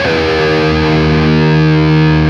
LEAD D#1 CUT.wav